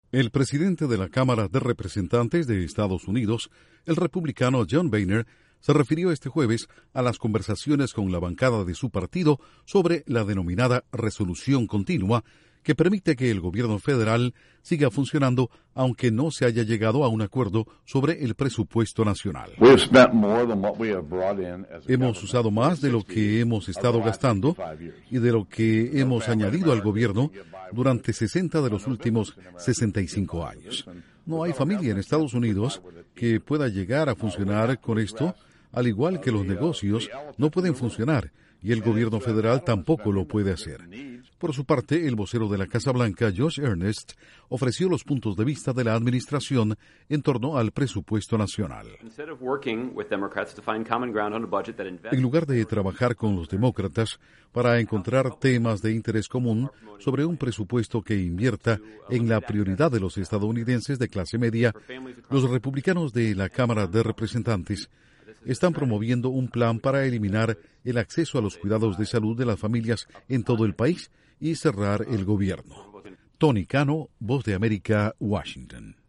Republicanos y demócratas se culpan mutuamente por posible cierre del gobierno federal de Estados Unidos. Informa desde la Voz de América en Washington